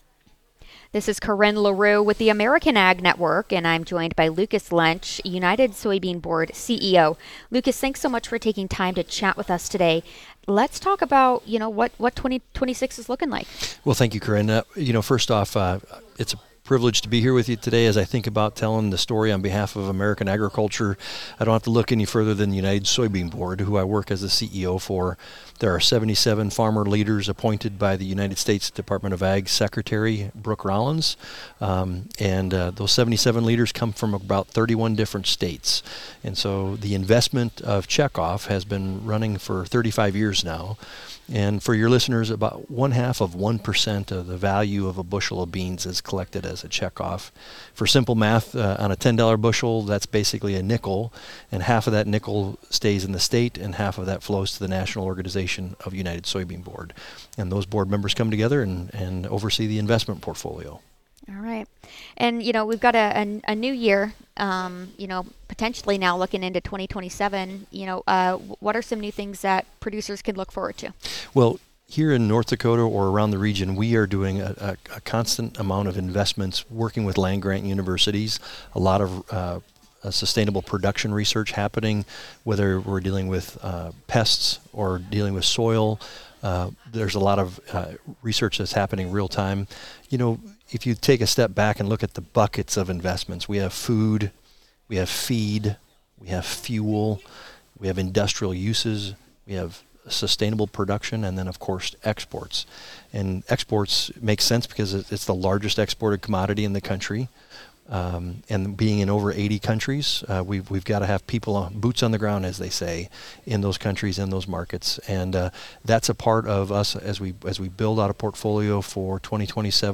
In an interview with the American Ag Network